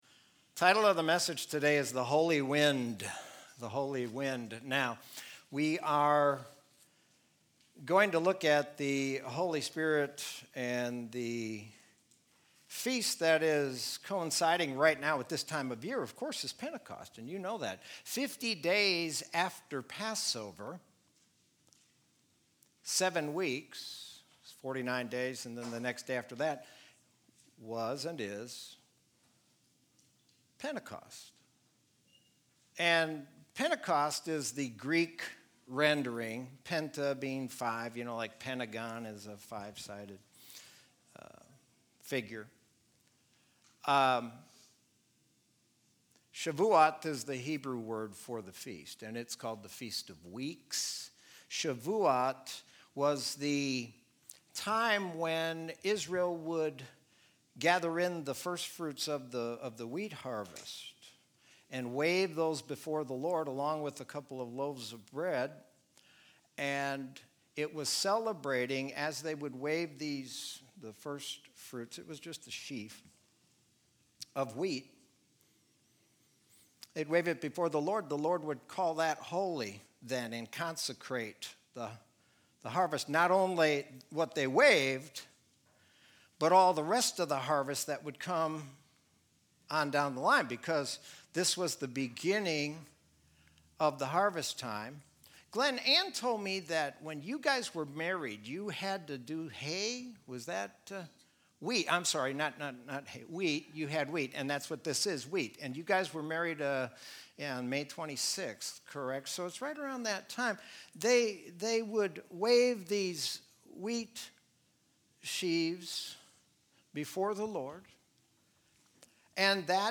Sermon from Sunday, May 31, 2020.